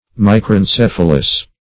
Search Result for " micrencephalous" : The Collaborative International Dictionary of English v.0.48: Micrencephalous \Mi`cren*ceph"a*lous\, [Micr- + Gr.
micrencephalous.mp3